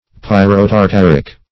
Search Result for " pyrotartaric" : The Collaborative International Dictionary of English v.0.48: Pyrotartaric \Pyr`o*tar*tar"ic\, a. [Pyro- + tartaric.]
pyrotartaric.mp3